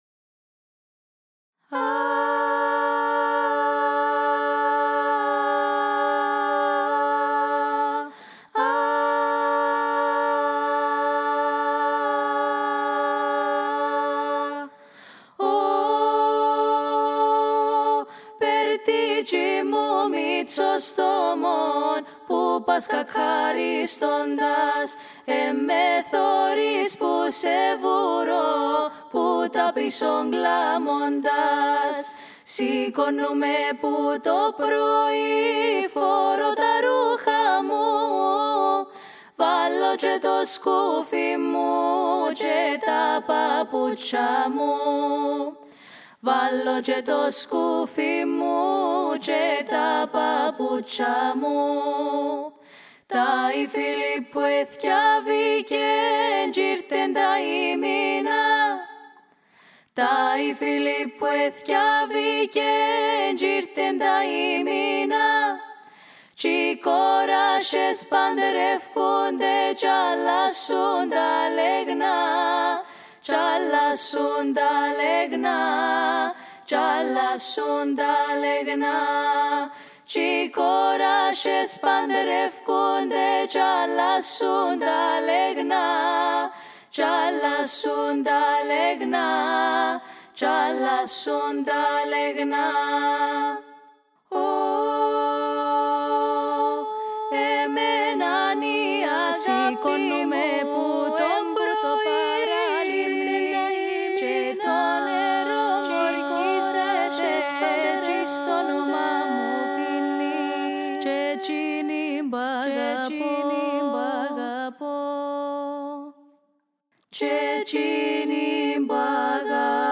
Η κυπριακή παραδοσιακή μουσική
2139_03.ΠΟΛΥΦΩΝΙΚΟ.mp3